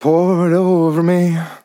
Pour it OVER ME Vocal Sample
DISCO VIBES | dry | english | it | LYRICS | male
Categories: Vocals
man-disco-vocal-fills-120BPM-Fm-9.wav